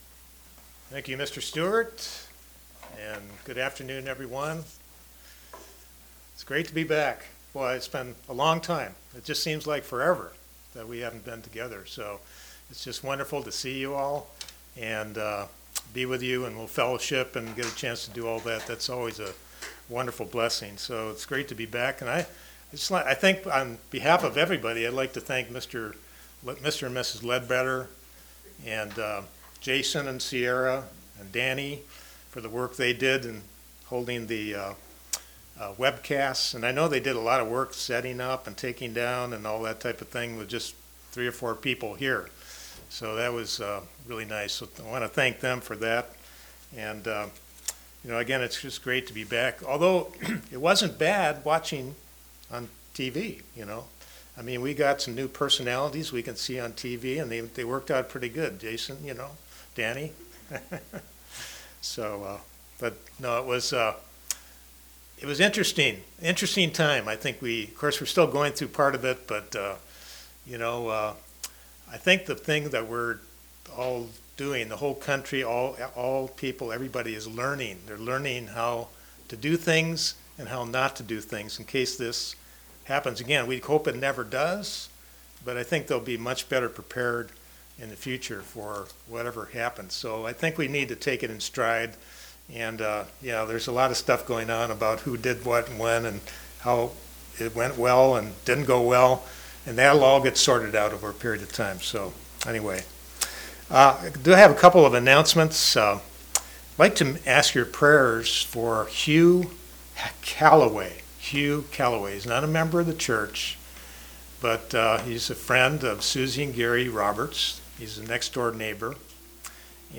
Sermons
Given in Knoxville, TN London, KY